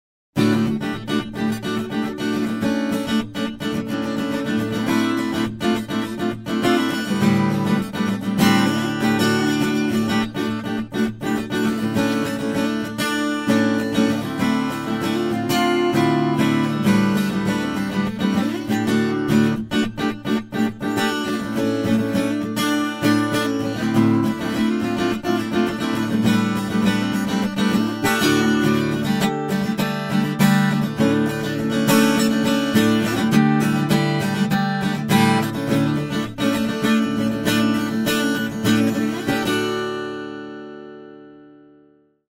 Mellow DADGAD tuning ideas on guitar